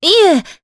Veronica-Vox-Deny_jp.wav